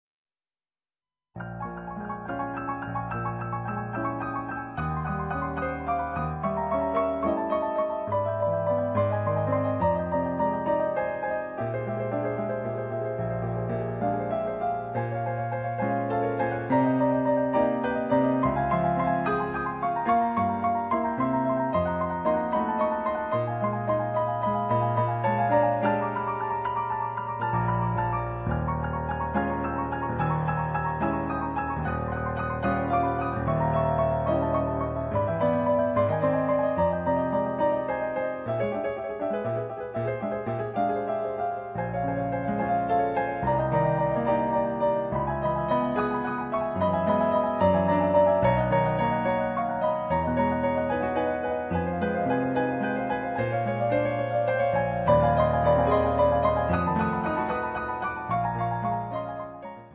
pianoforte
dal suono avvolgente e trascinante.